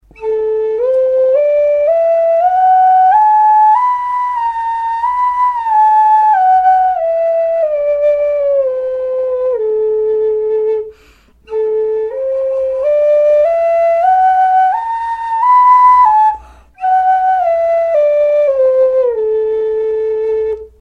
Пимак, индейская флейта, из ольхи, малый
Пимак - традиционная флейта северо-американских индейцев. Отличительная черта - наличие промежуточной камеры, сглаживющей неровности дыхания.
Звучание глубокое, нежное.
Строй - ля.